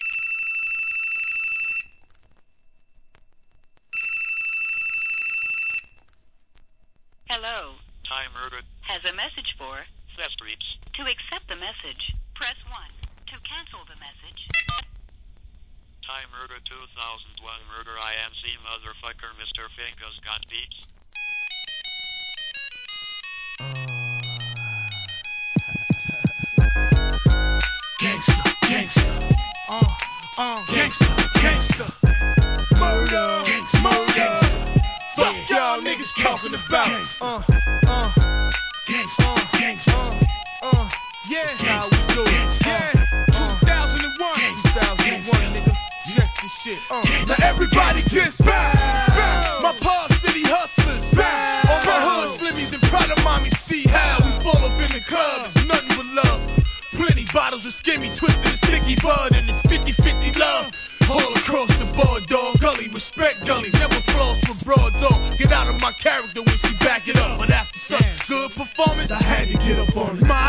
HIP HOP/R&B
当時の携帯着信音のようなメロディーを使用した上音が斬新でした!!